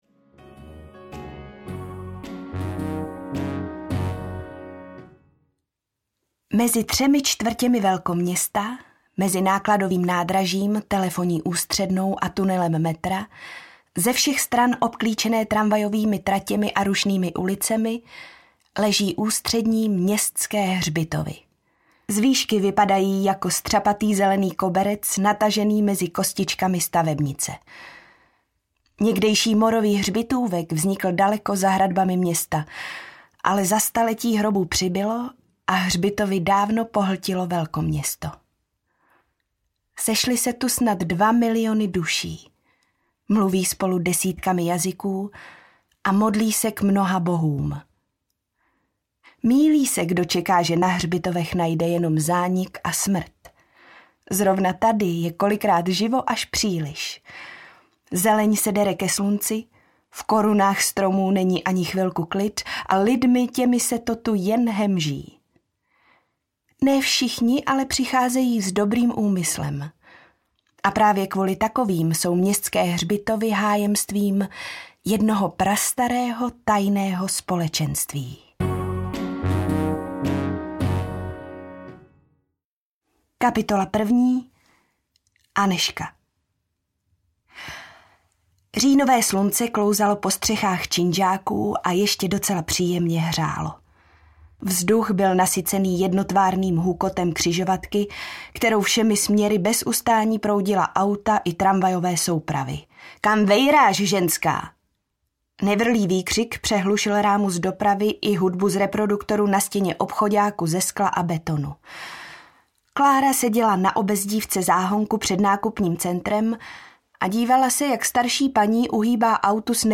Tajemství Hrobaříků audiokniha
Ukázka z knihy